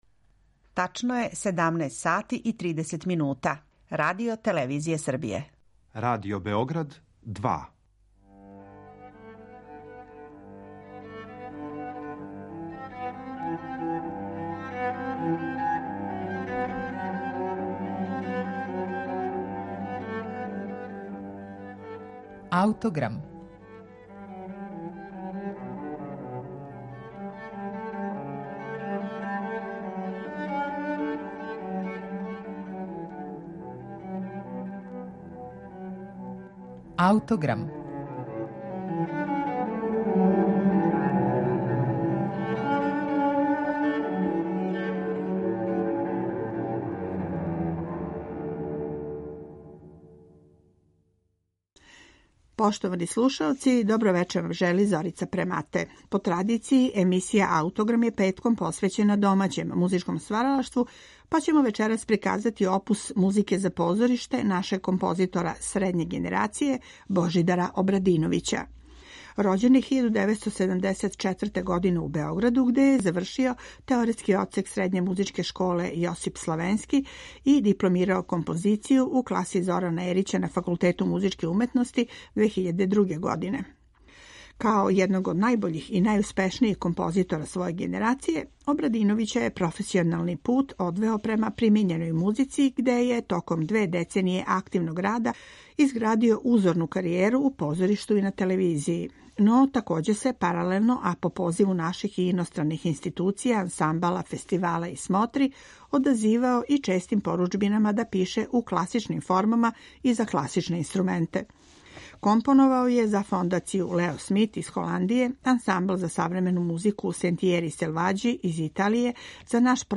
музику за позориште